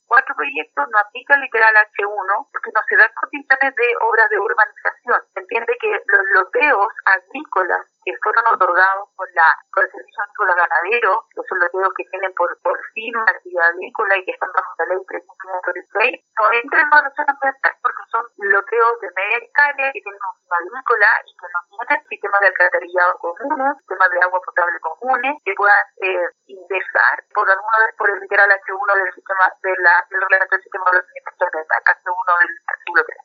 La directora regional del SEA, Karina Bastidas, expuso las razones por las cuales los otros cuatro loteos: Cutipay I, Cutipay II, Pilolcura y Los Pellines Predio Canelales, no deberían ser evaluados ambientalmente, lo cual se determinó en base a la información entregada por la SMA.